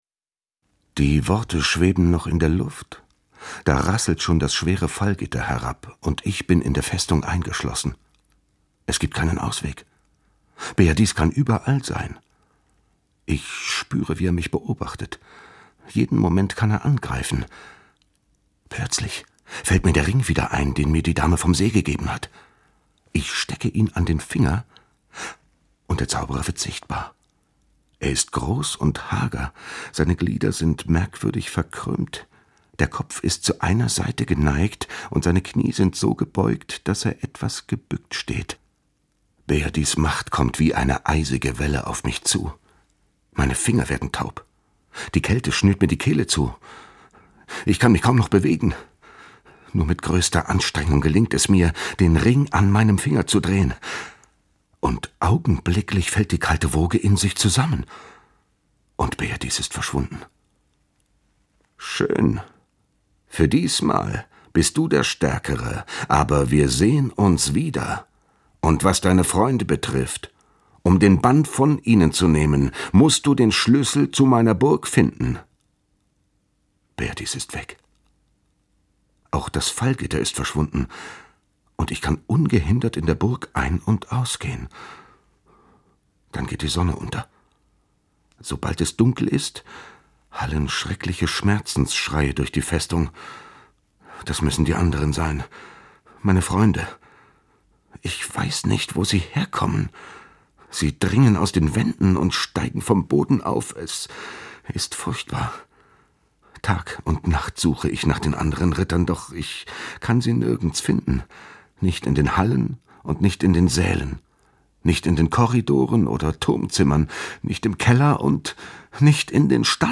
Nicht nur, aber auch, mit seiner erfahrenen Stimme, die mit ihrem bassig, knusprigem Timbre wohligen Charme versprüht.
Hoerbuch-Lancelot-mp3-Kopie.mp3